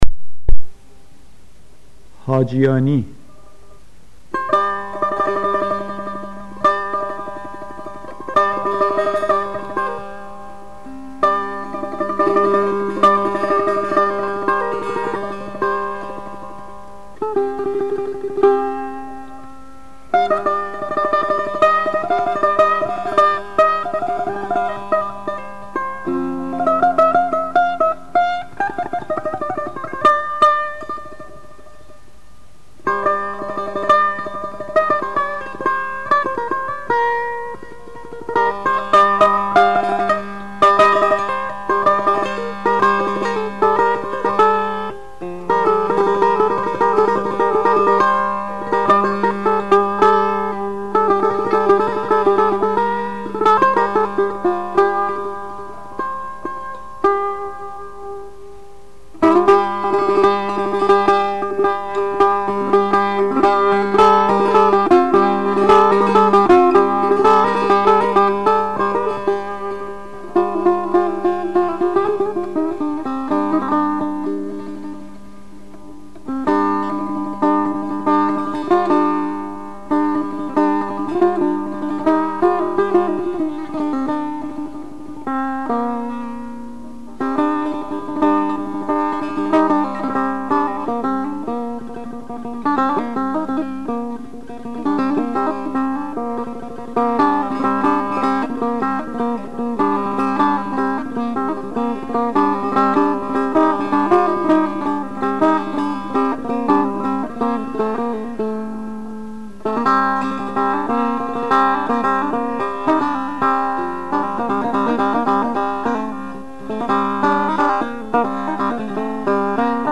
آواز دشتی ردیف میرزا عبدالله سه تار
حاجیانی، آواز دشتی